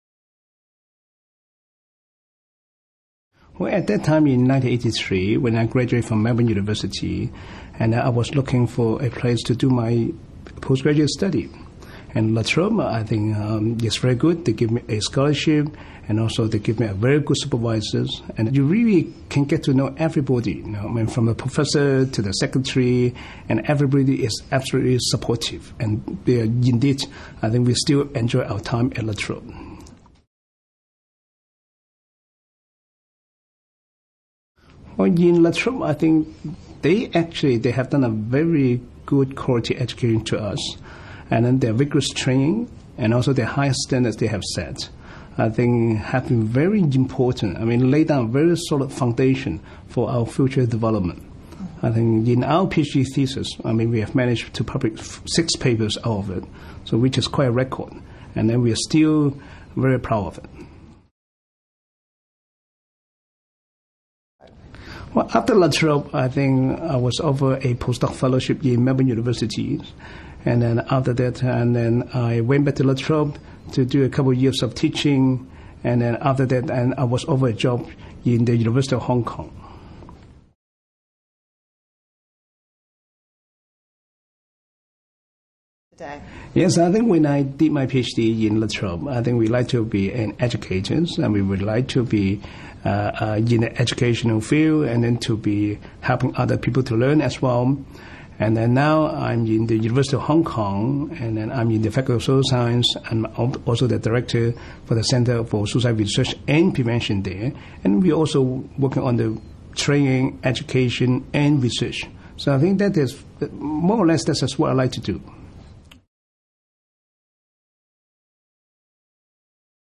We were fortunate to have the opportunity to interview some of the Distinguished Alumni Awards winners about their time at La Trobe University.